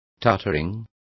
Complete with pronunciation of the translation of tottering.